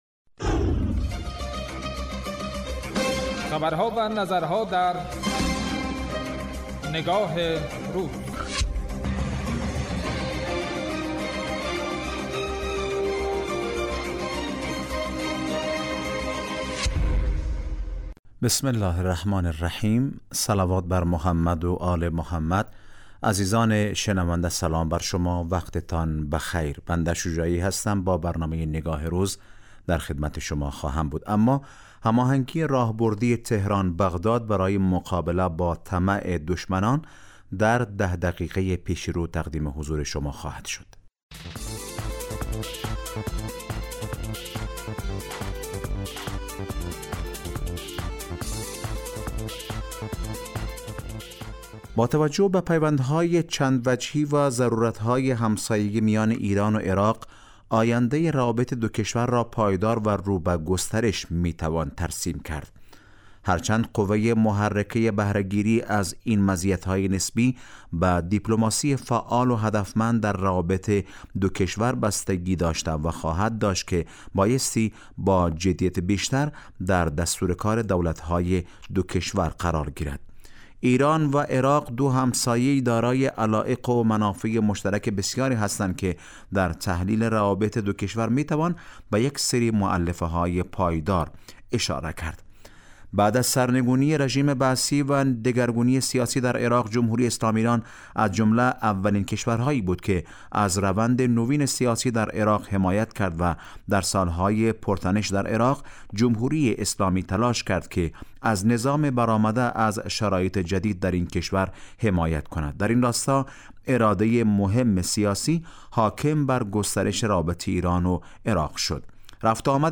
برنامه تحلیلی نگاه روز از شنبه تا پنجشنبه راس ساعت 14 به مدت 10 دقیقه پخش می گردد